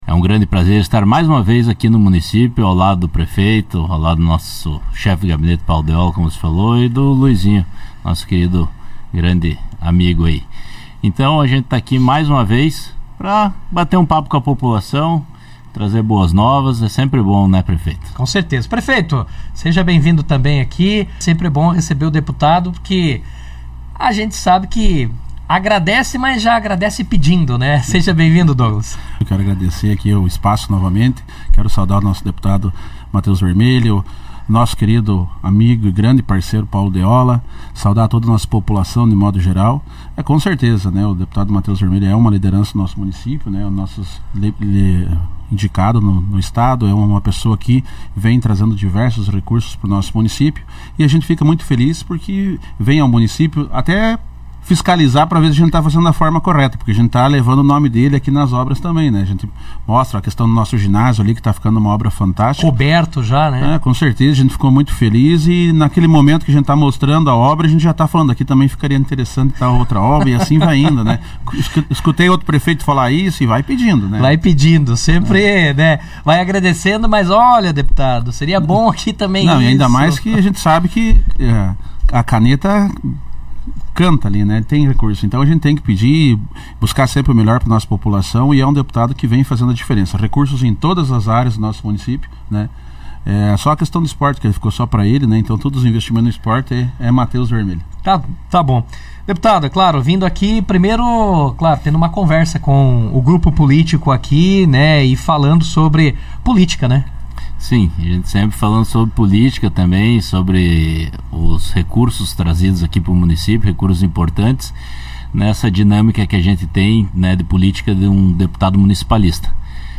Prefeito Douglas Potrich e deputado estadual Matheus Vermelho destacam parceria e avanços para Ampére em entrevista ao Jornal RA 2ª Edição
O prefeito de Ampére, Douglas Potrich, e o deputado estadual Matheus Vermelho participaram nesta quinta-feira, 06, do Jornal RA 2ª Edição, onde falaram sobre a gestão municipal e estadual, com foco nas ações e investimentos realizados em Ampére. Durante a entrevista, ambos destacaram a parceria política que tem garantido importantes recursos estaduais para o município, especialmente nas áreas de infraestrutura, esporte, saúde e educação.